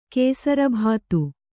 ♪ kēsarabhātu